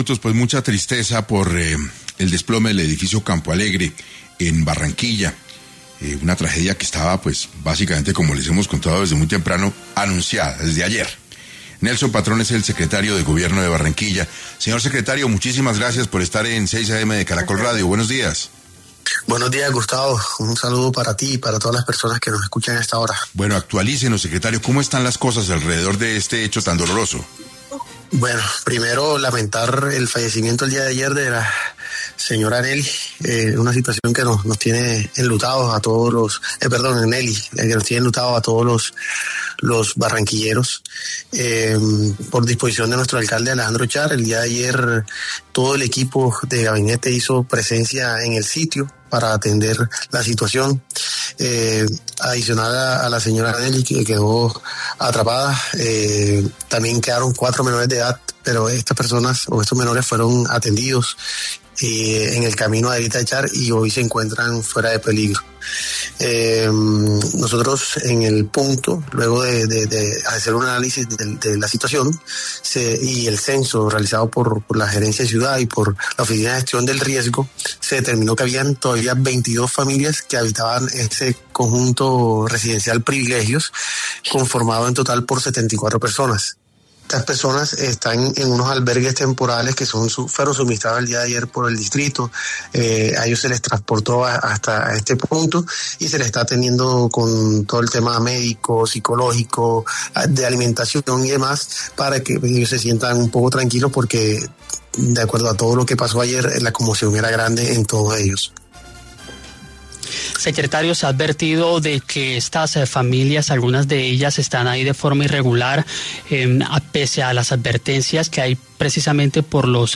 Nelson Patrón, secretario de Gobierno de Barranquilla, estuvo en Caracol Radio, esta emergencia dejó una mujer muerta y tres menores heridos.